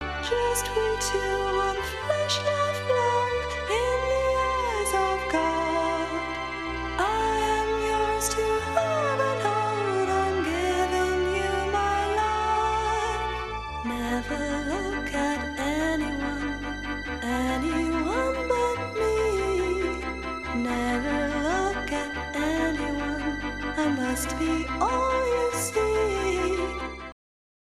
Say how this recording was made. Stereo excerpt